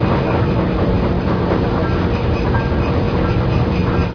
techage_oildrill.ogg